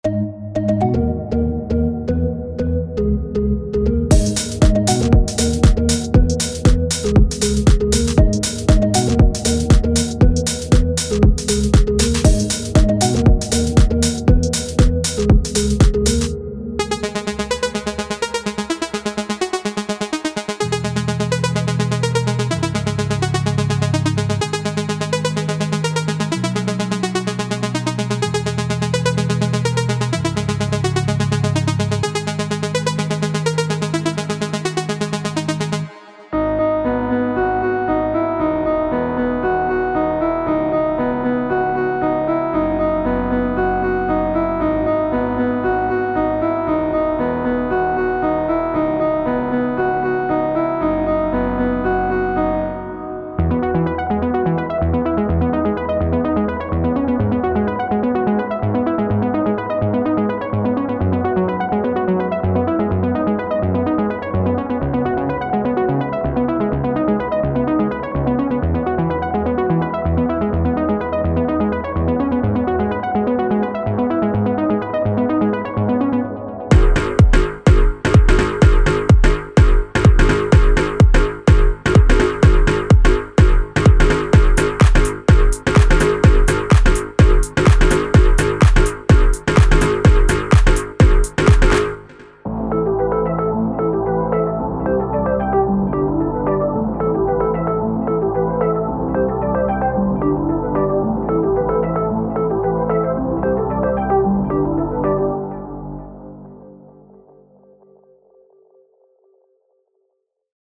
Innovative workstation synthesizer
Arpeggiators.mp3